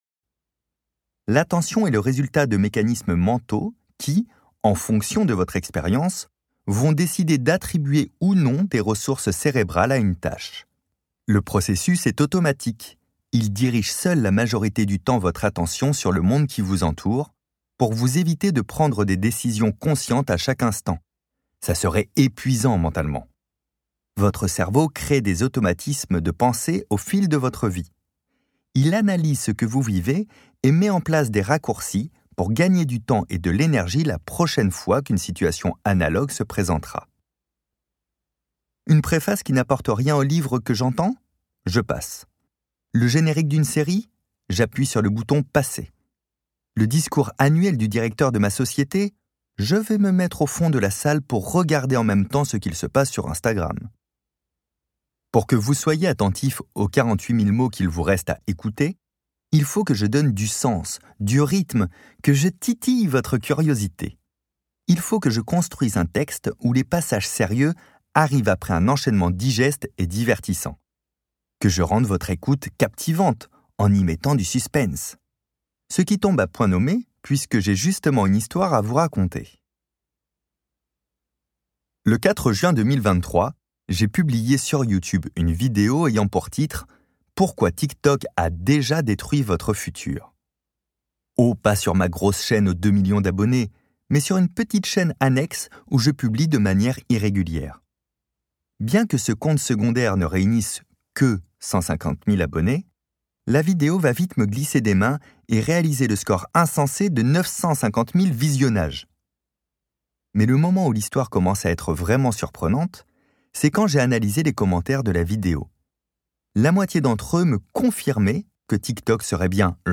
Extrait gratuit - Votre attention est votre superpouvoir de Fabien OLICARD